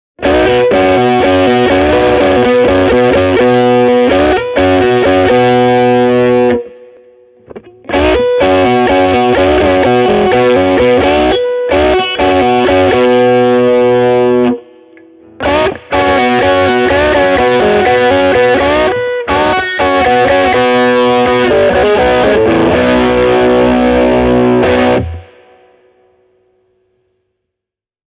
Tiheämpiä särösoundeja varten tarvitsee kuitenkin myös SG:n kanssa säröpedaalia:
SG + säröpedaali (Boss SD-1)
sg-e28093-overdrive-pedal.mp3